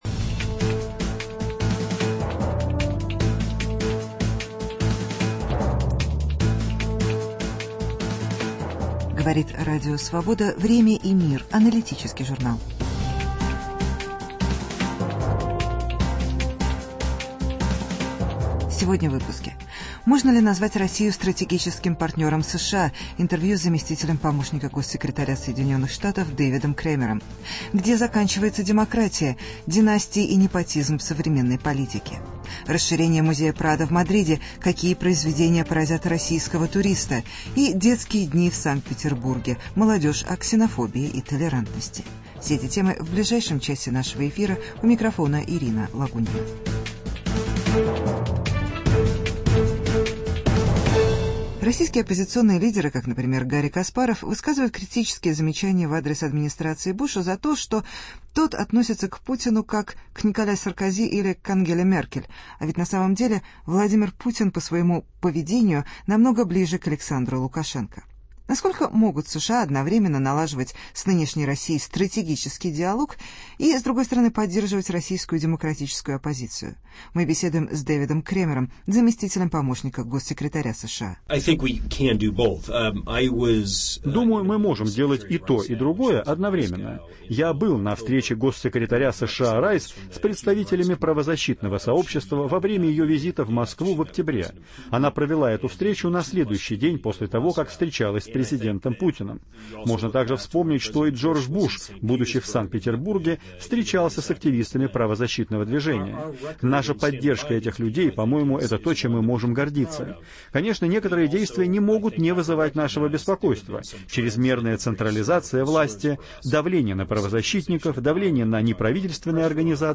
Интервью с заместителем государственного секретаря США Дэвидом Кремером. Династии в современной политике. Новые экспозиции в обновленном мадридском музее Прадо.